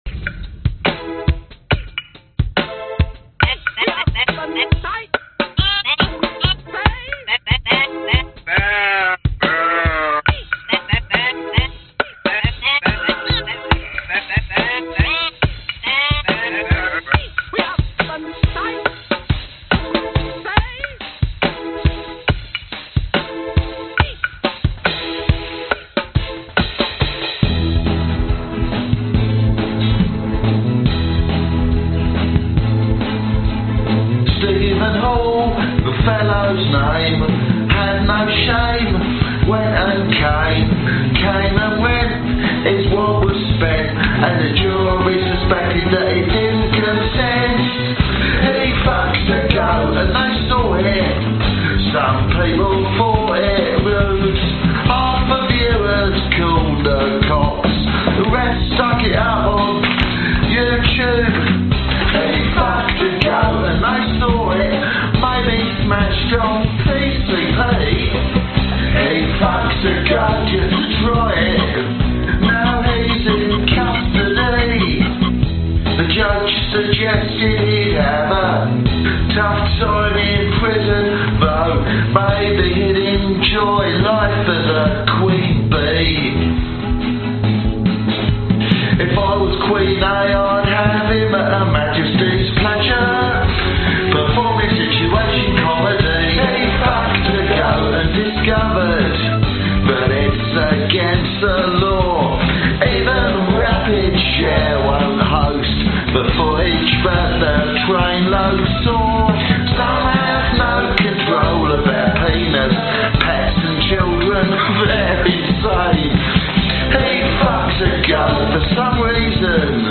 Tag: 男声 电子 吉他 贝司 合成器 循环 人声